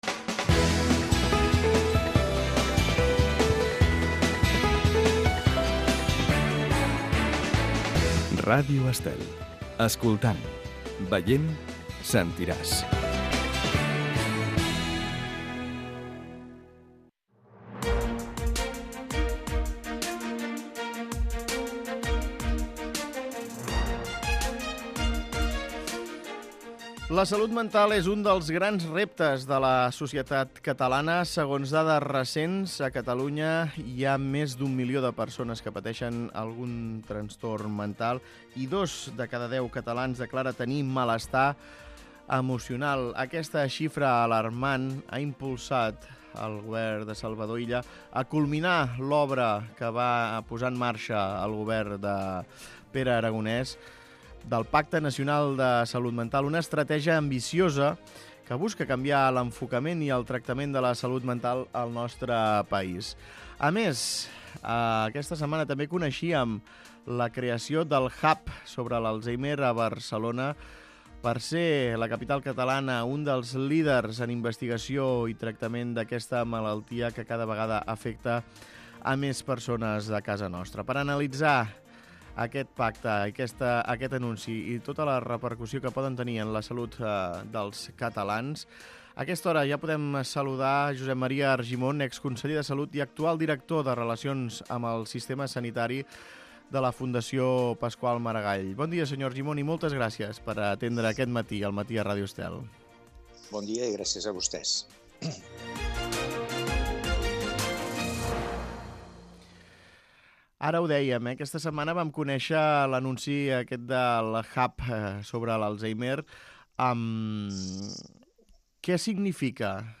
Escolta l'entrevista amb Josep Maria Argimon, exconseller de Salut